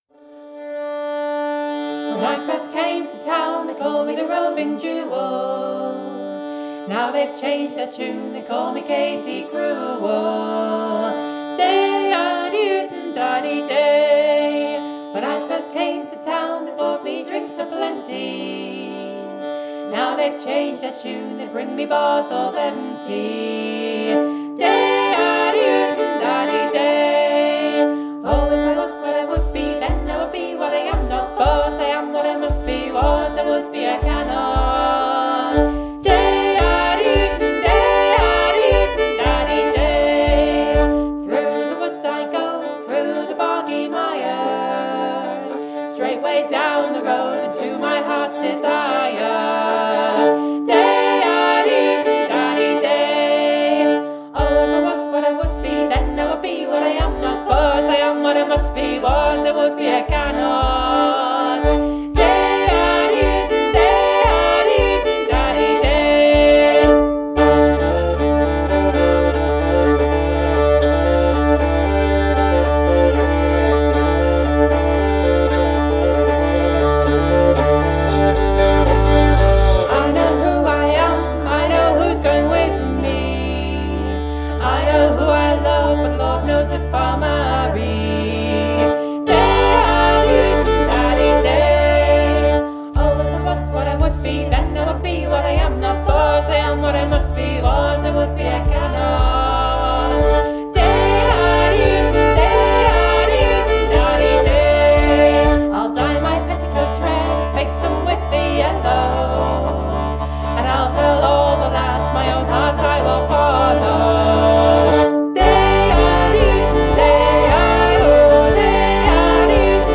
Genre: Folk